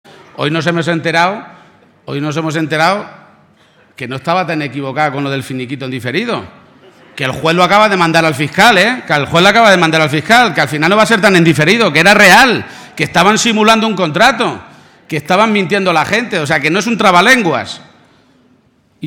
Audio Page-acto Albacete 2